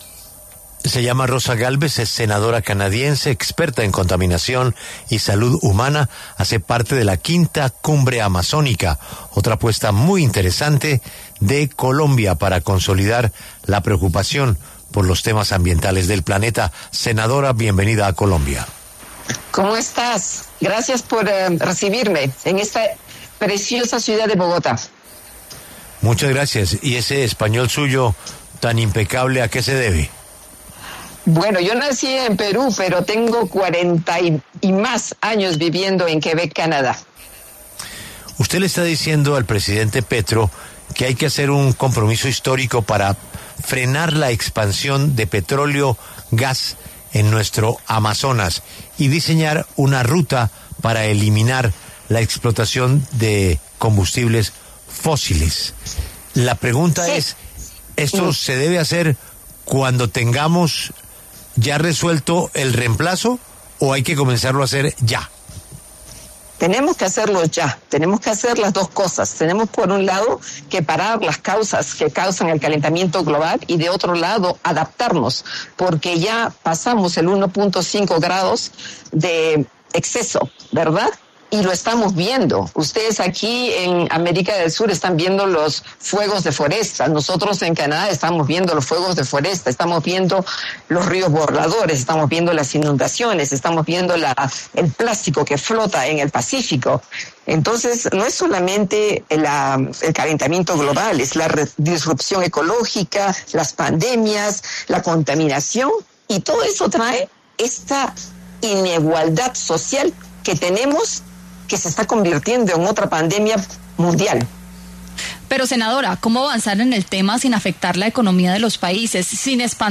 La senadora canadiense Rosa Gálvez conversó con La W sobre las propuestas de transición energética que compartió con el presidente Petro.